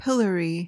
PRONUNCIATION: (PIL-uh-ree) MEANING: verb tr.: To subject to severe public criticism or ridicule. noun: A device used in the past to publicly punish offenders by locking their head and hands in place.